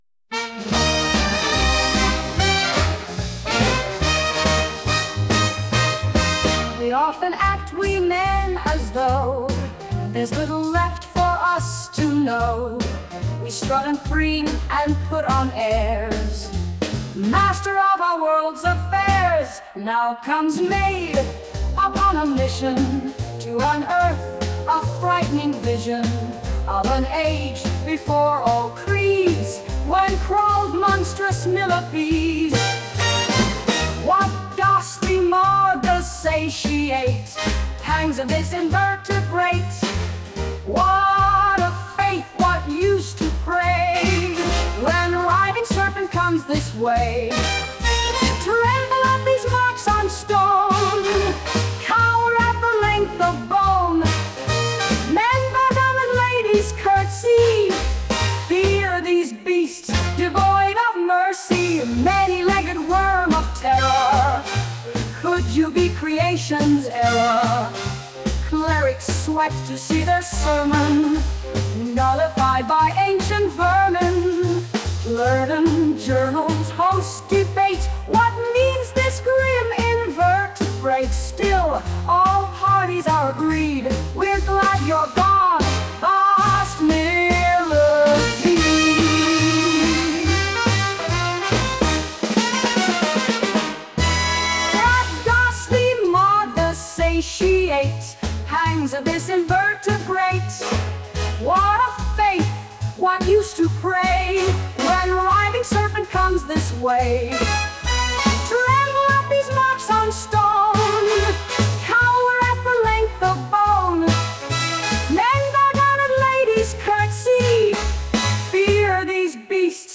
Big Band Version